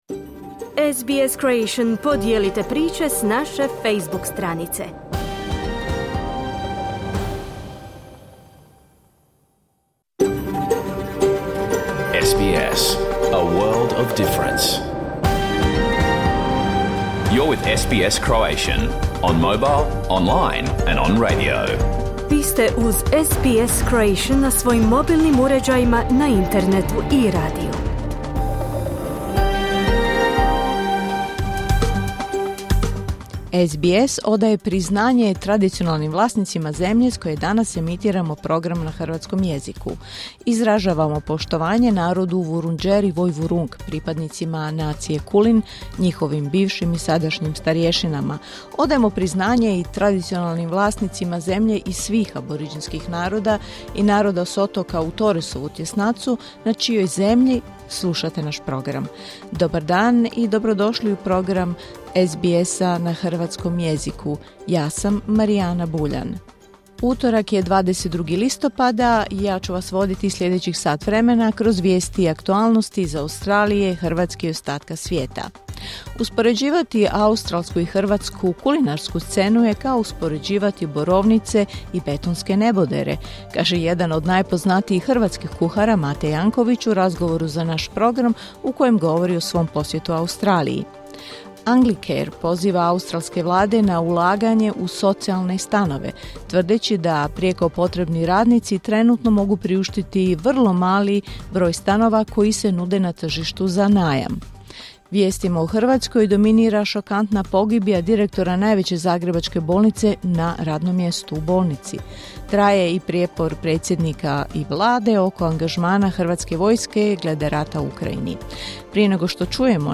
Vijesti i aktualnosti iz Australije, Hrvatske i ostatka svijeta. Program je emitiran uživo u utorak, 22. listopada , u 11 sati po istočnoaustralskom vremenu.